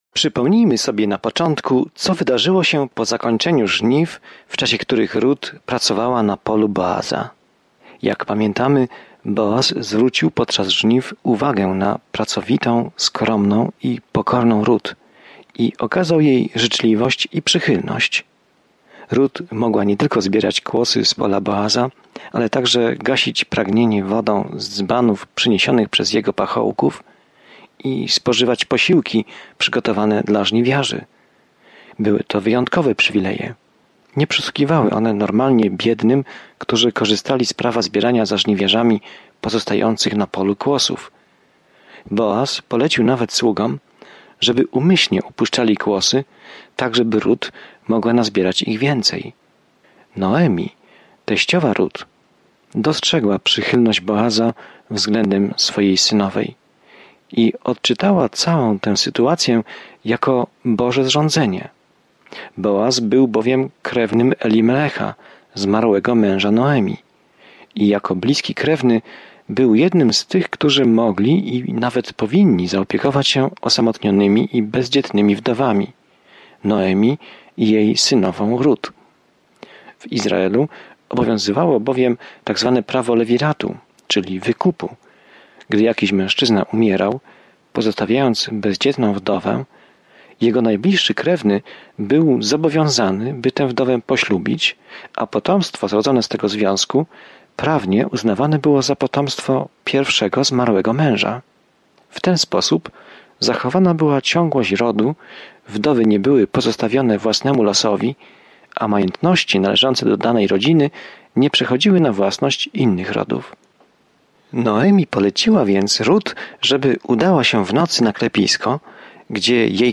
Pismo Święte Rut 3:6-18 Dzień 4 Rozpocznij ten plan Dzień 6 O tym planie Rut, historia miłosna odzwierciedlająca miłość Boga do nas, opisuje długą historię – w tym historię króla Dawida… a nawet historię Jezusa. Codziennie podróżuj przez Rut, słuchając studium audio i czytając wybrane wersety słowa Bożego.